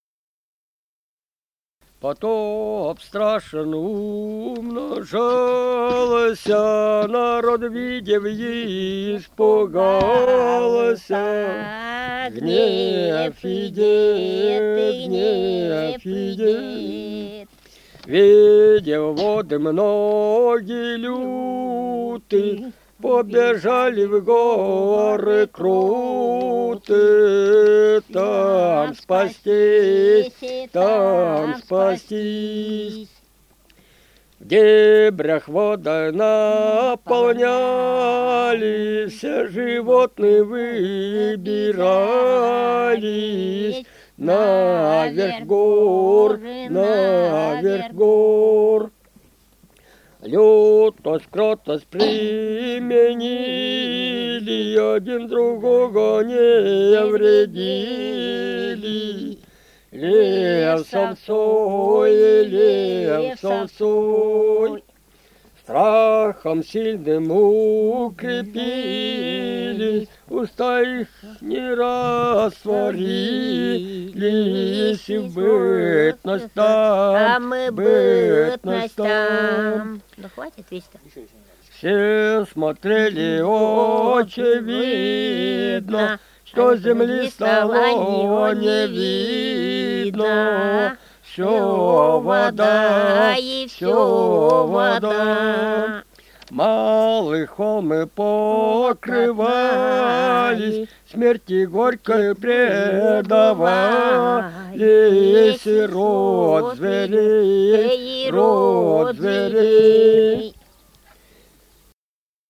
Русские песни Алтайского Беловодья 2 «Потоп страшен умножался», о Страшном Потопе.
Республика Алтай, Усть-Коксинский район, с. Тихонькая, июнь 1980.